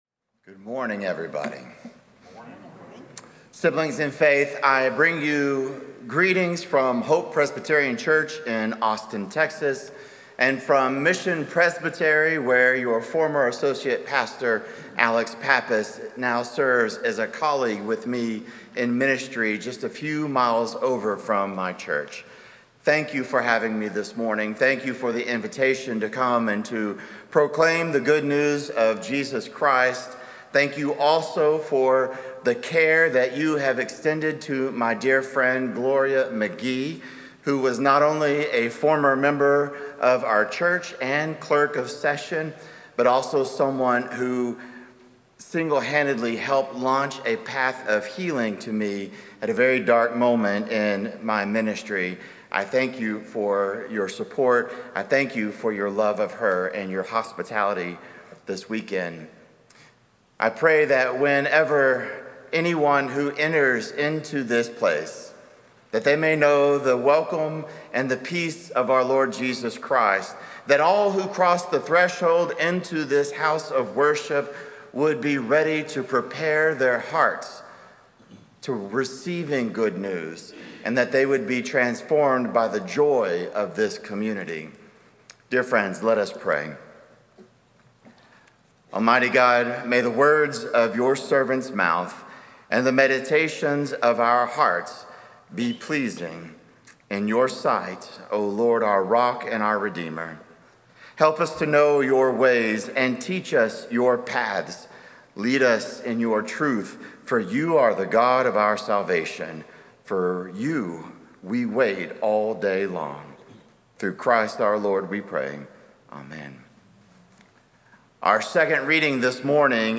Audio Sermons details